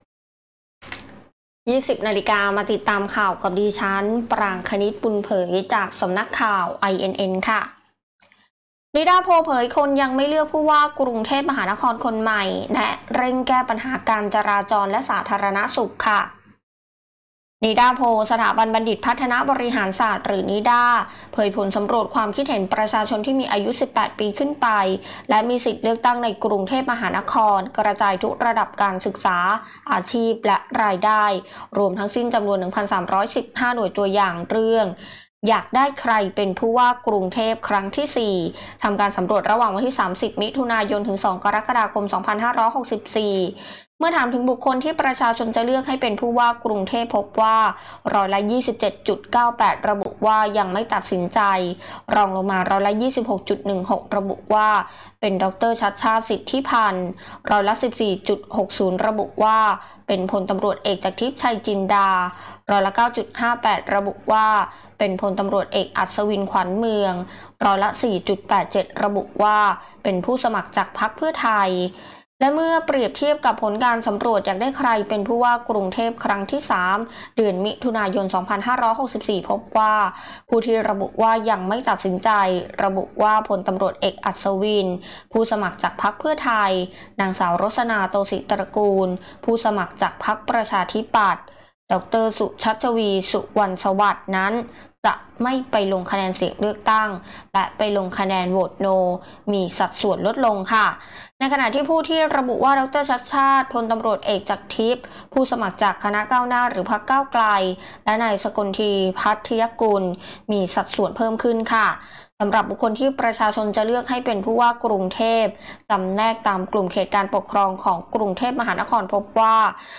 คลิปข่าวต้นชั่วโมง
ข่าวต้นชั่วโมง 20.00 น.